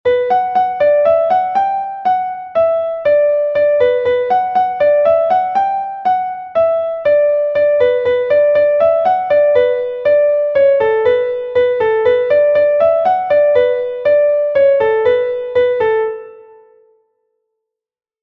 Gavotte de Bretagne